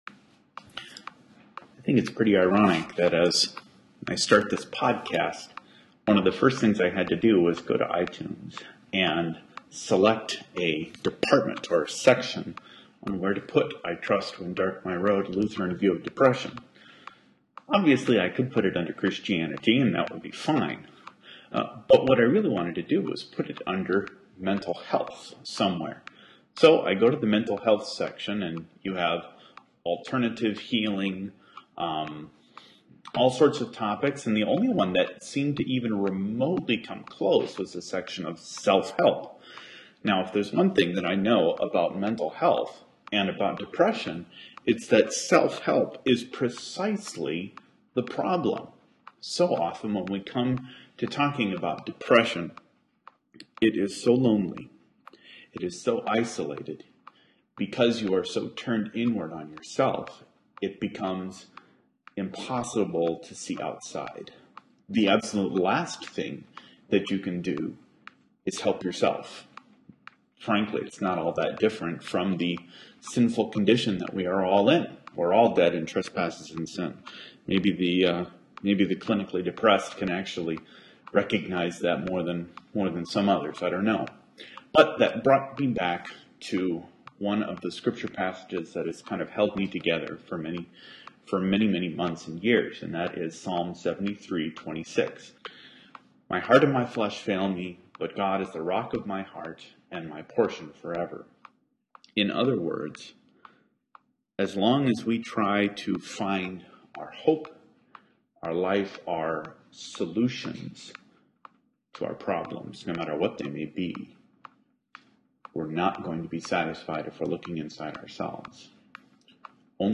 Â It is my hope to have a short meditative type podcast available each day.